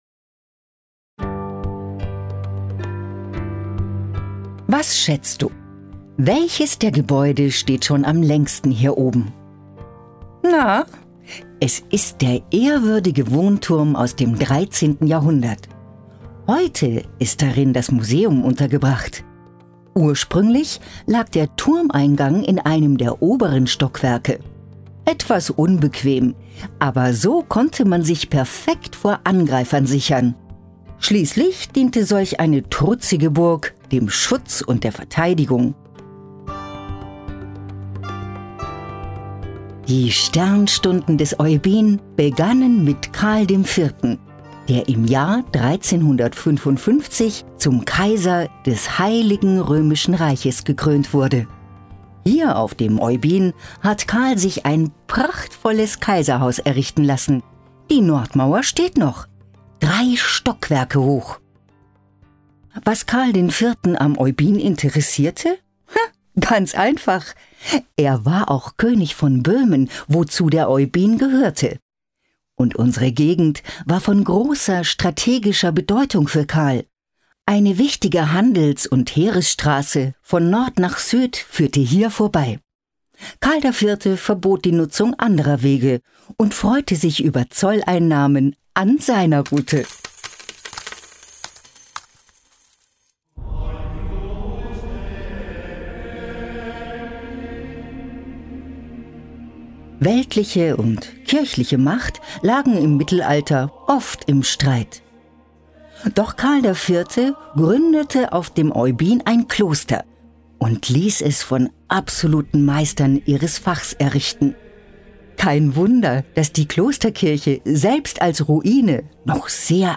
Erlebe das mittelalterliche Leben auf Burg und Kloster Oybin mit einem Audioguide und entdecke spannende Geschichten und Erlebnisse in Schlösserland Sachsen.
Erlebe das mittelalterliche Leben auf Burg und Kloster Oybin Begleite die Hofmeisterin auf einem spannenden Audioguide-Rundgang und lausche ihren Erzählungen über das mittelalterliche Leben und die Sagen von Oybin.